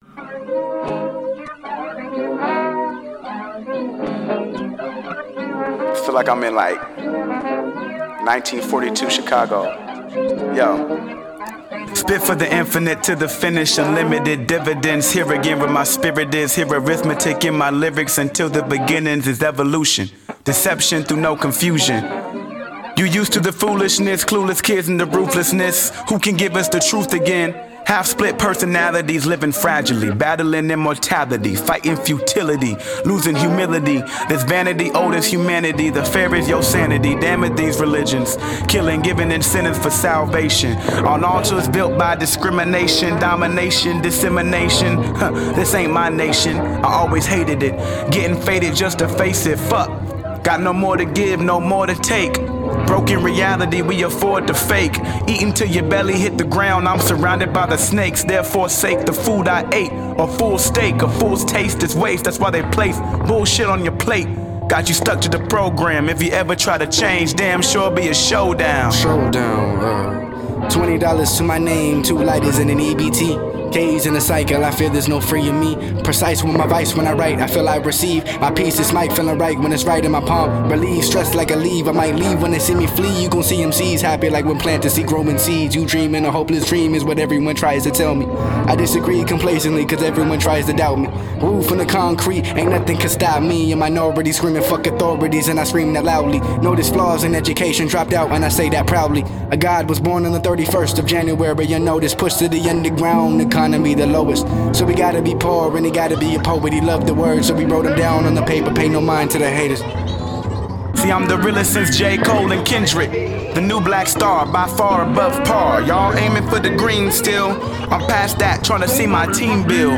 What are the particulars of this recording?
Recorded at AD1 Studios, Seattle, WA